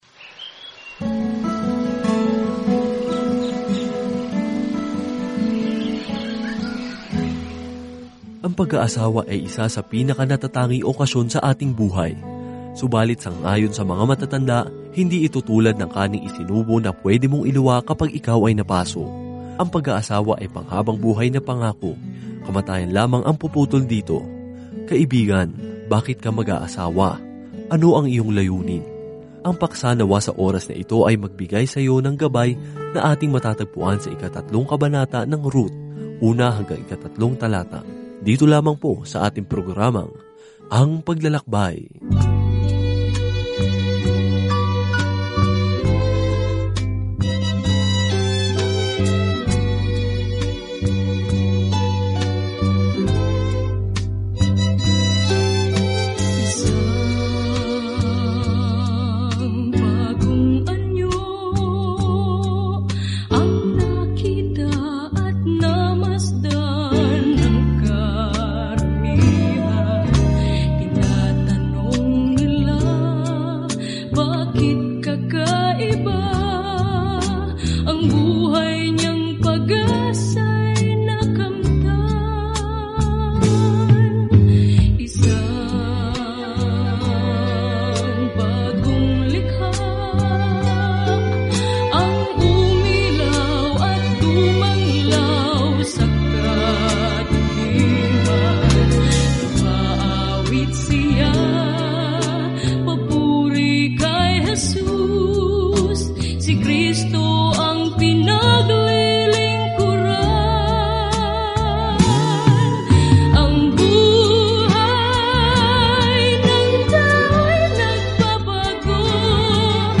Banal na Kasulatan Ruth 3:1-3 Araw 4 Umpisahan ang Gabay na Ito Araw 6 Tungkol sa Gabay na ito Si Ruth, isang kuwento ng pag-ibig na sumasalamin sa pag-ibig ng Diyos sa atin, ay naglalarawan ng mahabang pananaw sa kasaysayan–kabilang ang kuwento ni haring David... at maging ang backstory ni Jesus. Araw-araw na paglalakbay kay Ruth habang nakikinig ka sa audio study at nagbabasa ng mga piling talata mula sa salita ng Diyos.